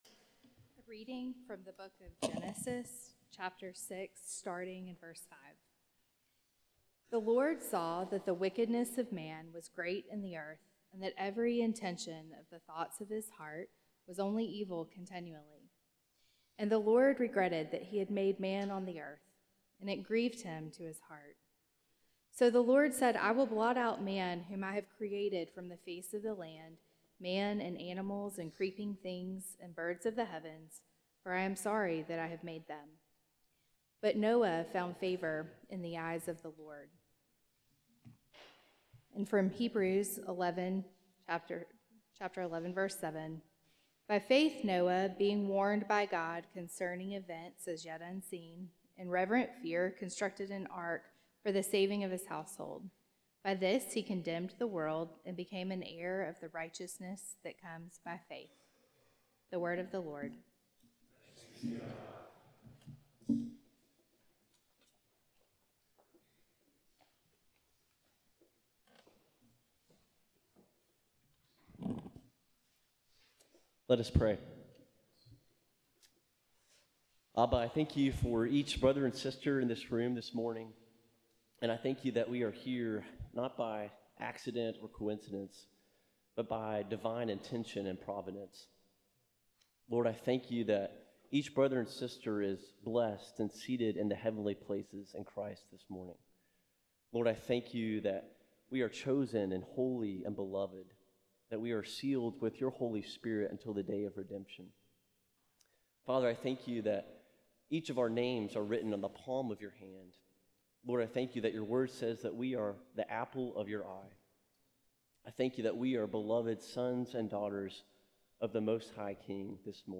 Subscribe to this feed to get past and future semester-long series of chapel sermons from Beeson Divinity School, Samford University, Birmingham, Alabama.